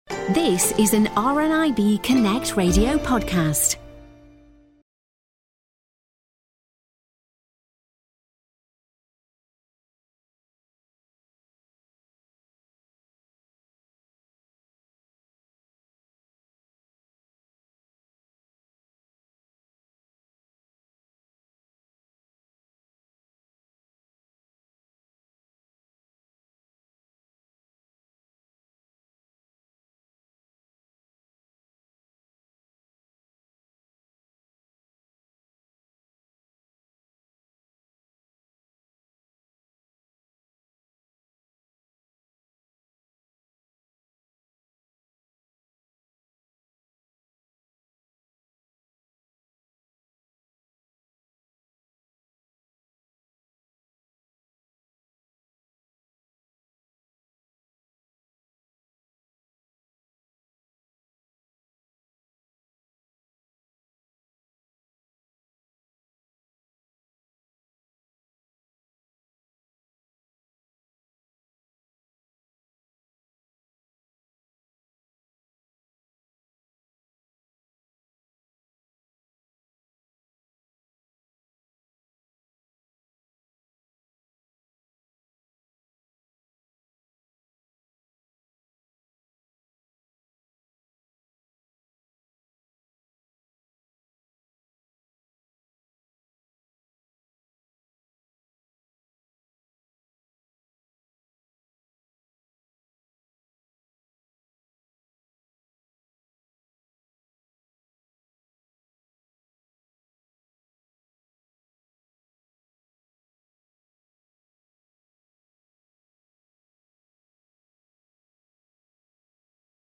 Making And Creating - Roundtable